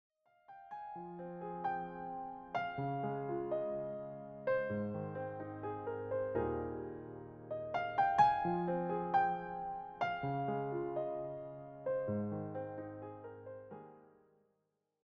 solo piano
Just calm and relaxing renditions of these well-known songs.